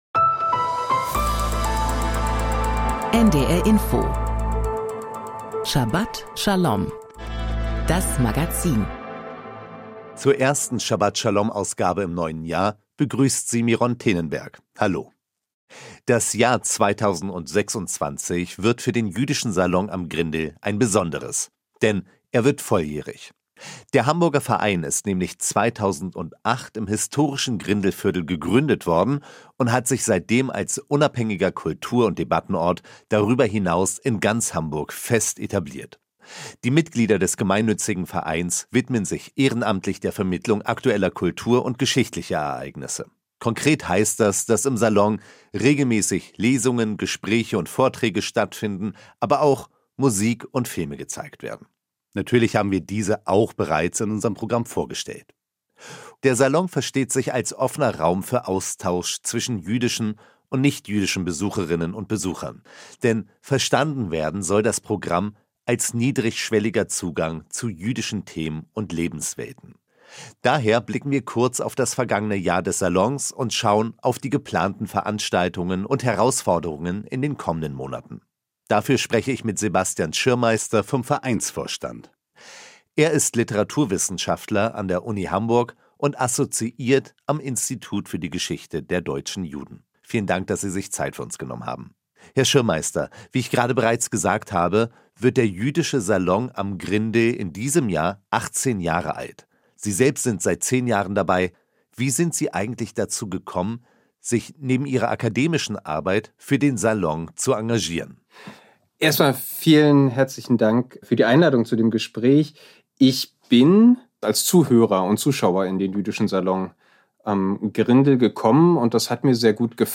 Interview
Thora-Auslegung